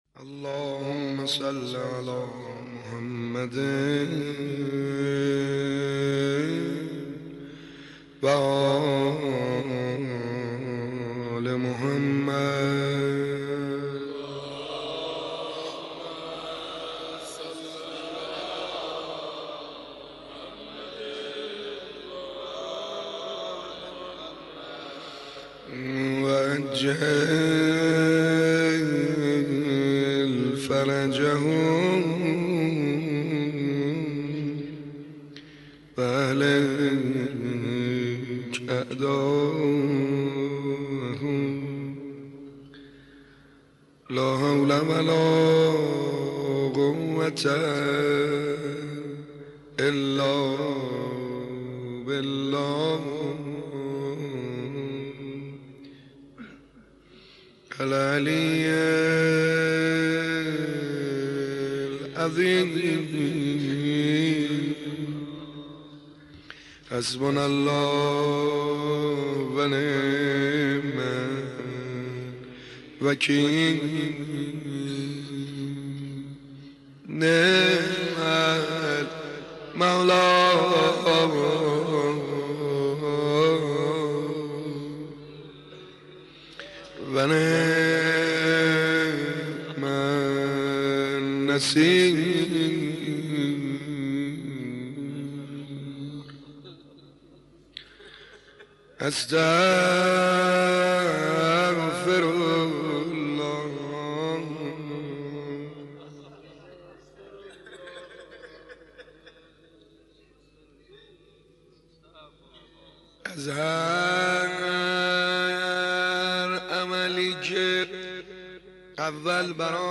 سعید حدادیان مداح
مناسبت : شب پنجم محرم